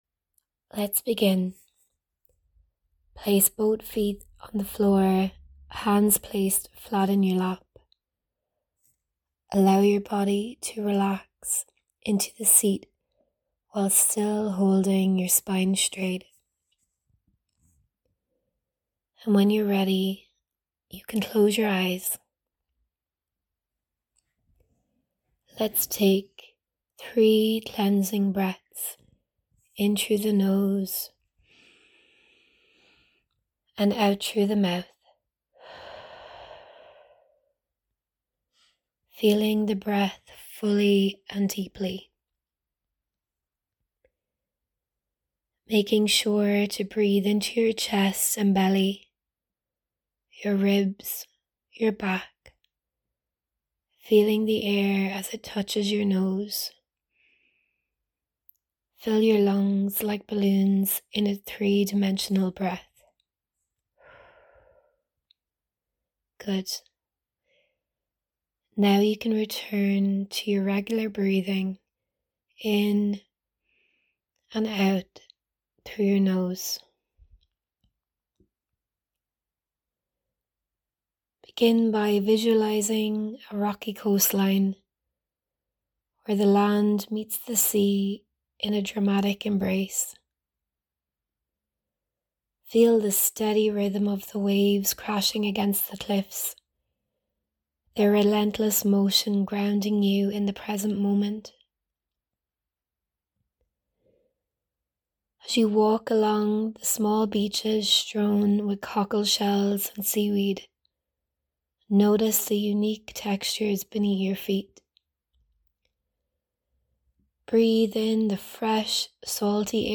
Celtic Symbols Meditation